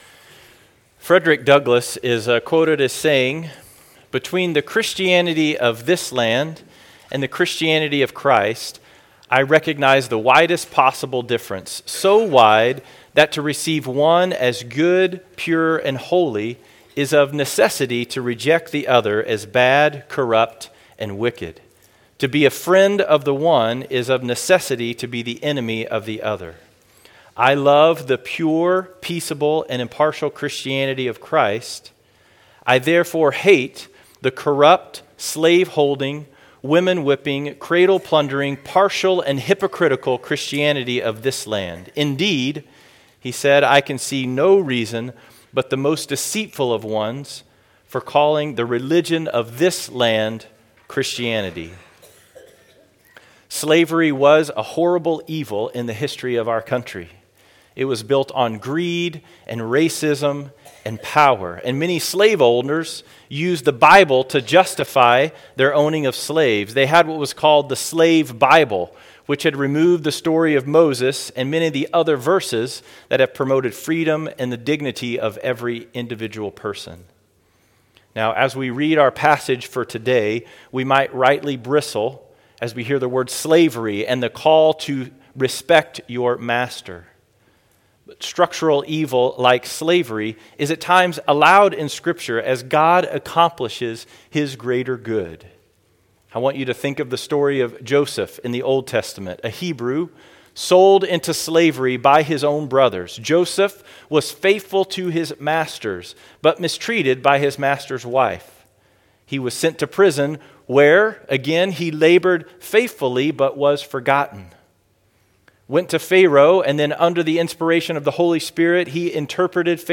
River Community Church Sunday Morning messages
Weekly message from River Community Church, Wichita, KS.